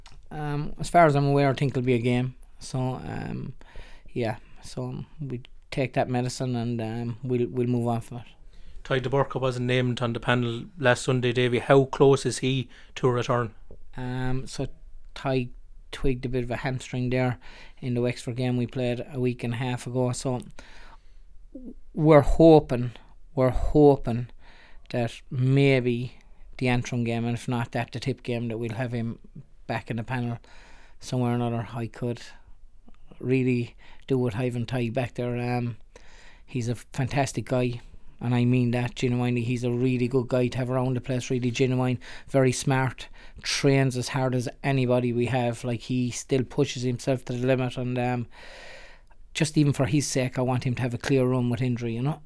Déise boss Davy Fitzgerald says that they will accept the punishment.